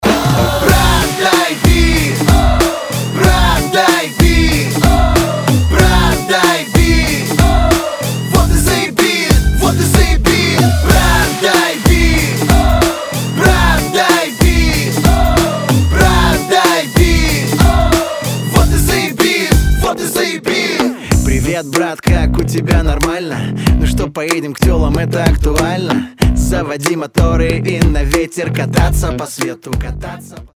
• Качество: 256, Stereo
Хип-хоп
dance